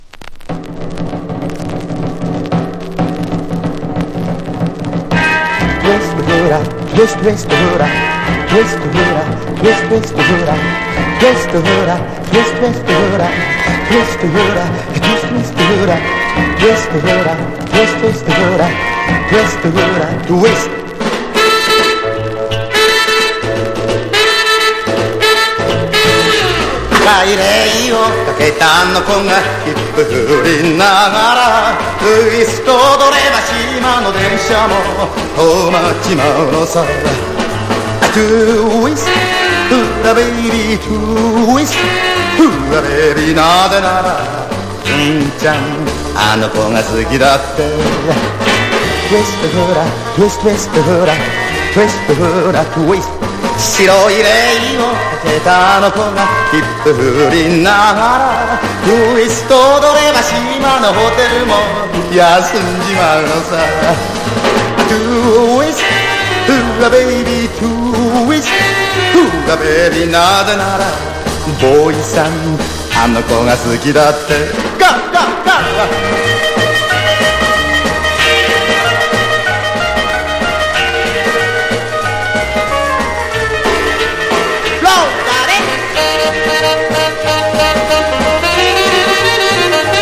60-80’S ROCK# 和モノ / ポピュラー